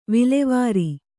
♪ vilevāri